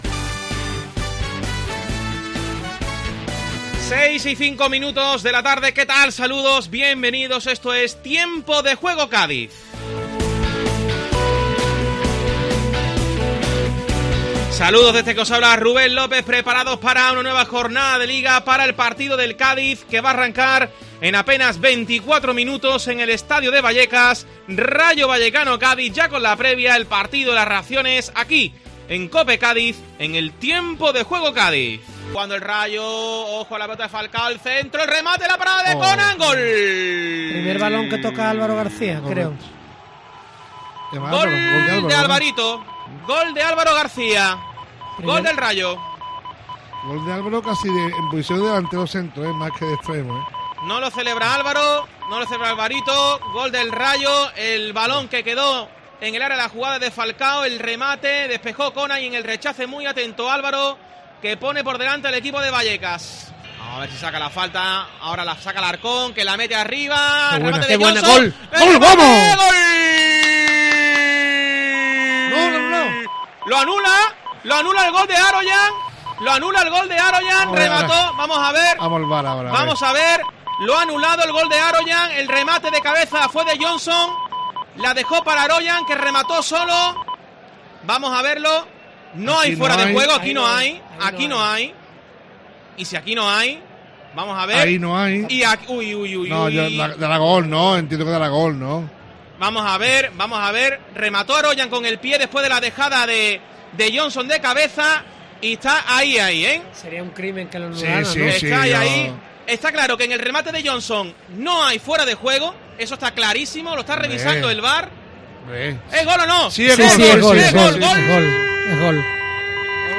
Escucha los mejores momentos de las casi tres horas de retransmisión del partido en los micrófonos de COPE Cádiz
El resumen sonoro del Rayo 3-1 Cádiz
Casi tres horas de retransmisión con toda la previa del choque, el encuentro y las reacciones postpartido.